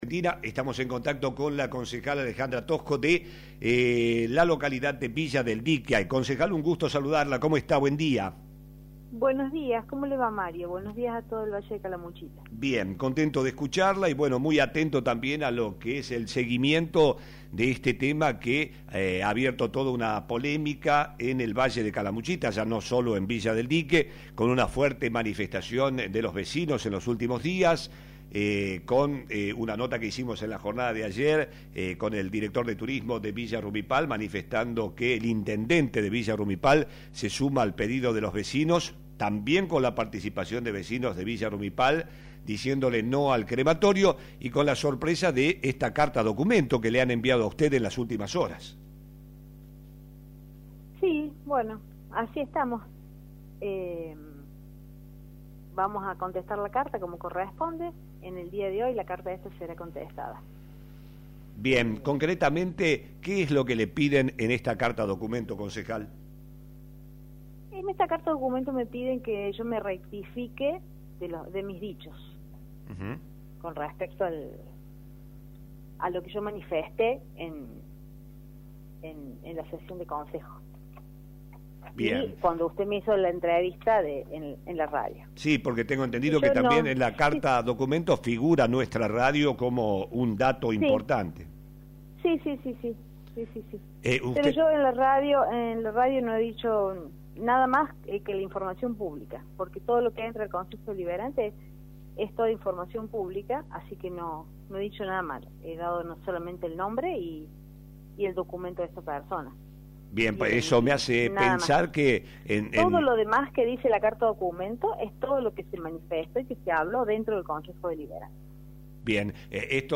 Alejandra Tosco, concejal de Villa del Dique habló hoy en Flash FM y confirmó que recibió una carta documento de parte del emprendedor que quiere llevar adelante el proyecto de crematorio en la localidad y adelantó que no se va a rectificar a la vez que dijo estar en contacto son sus abogados para responder la carta en las próximas horas. La edil se mostró contenta con el apoyo de toda las comunidades y agradeció al intendente de Villa Rumipal quien se manifestó en contra del proyecto.